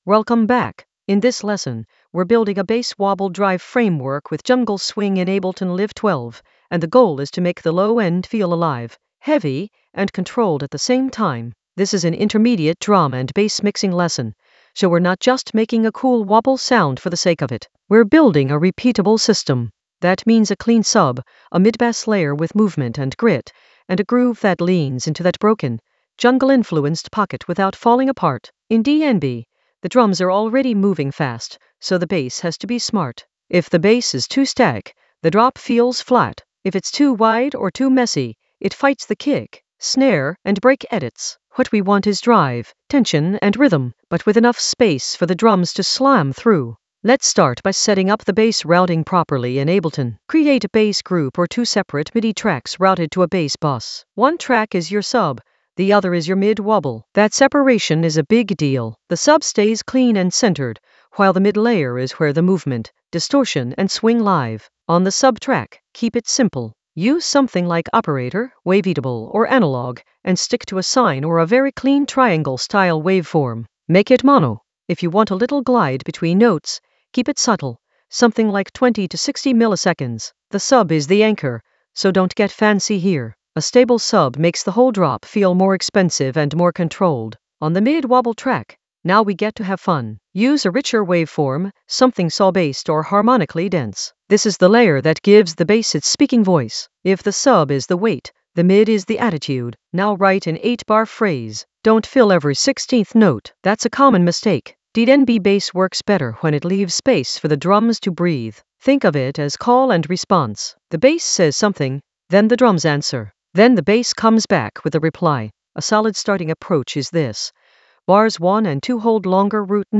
An AI-generated intermediate Ableton lesson focused on Bass wobble drive framework with jungle swing in Ableton Live 12 in the Mixing area of drum and bass production.
Narrated lesson audio
The voice track includes the tutorial plus extra teacher commentary.